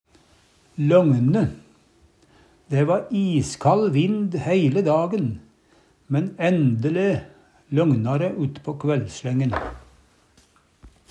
løgne - Numedalsmål (en-US)